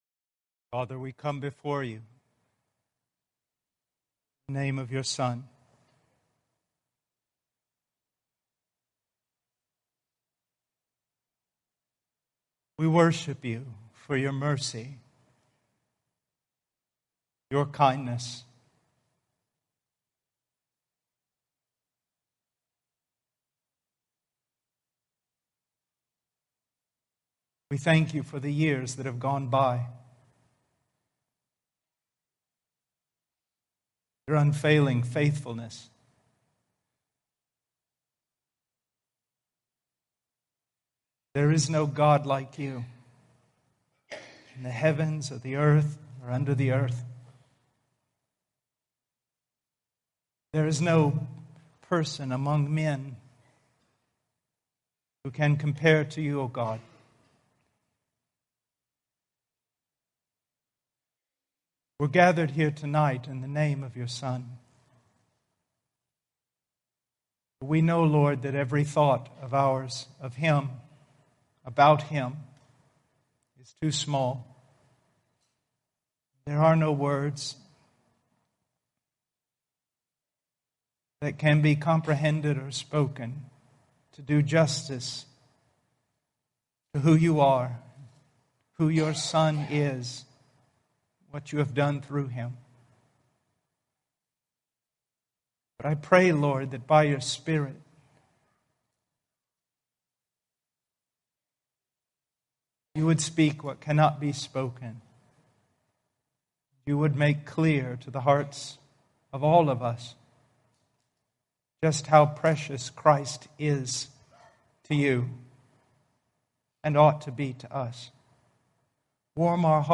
Preached in Fredericton, Canada at Devon Park Baptist Church